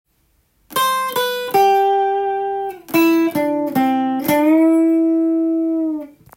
エレキギターで作りだす【羊文学のリズムをフレーズに変える方法】
①のフレーズはCメジャースケールの超定番
ドシソの動きになります。
２小節目でペンタトニックスケールを使えば